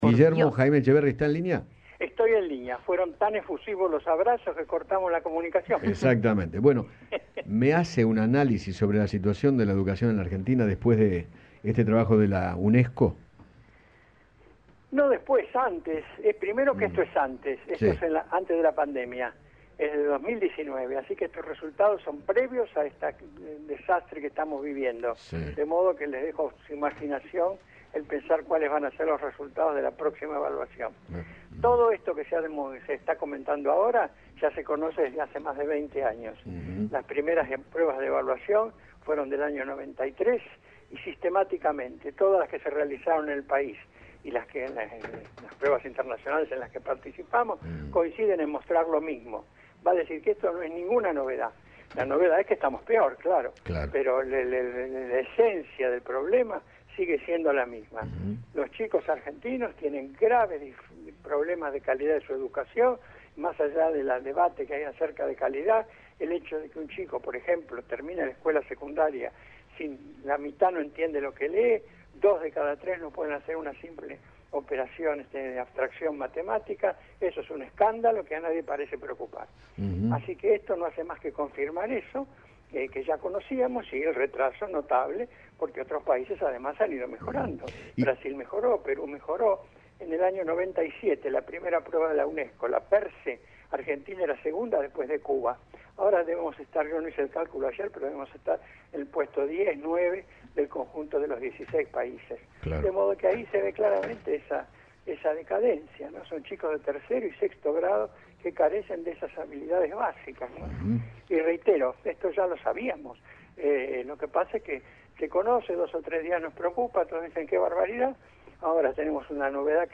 Guillermo Jaim Etcheverry, presidente de la Academia Nacional de Educación, conversó con Eduardo Feinmann sobre los preocupantes resultados de la Argentina en una prueba de la Unesco y sostuvo que “la  novedad es que estamos peor, pero la esencia del problema sigue siendo la misma”.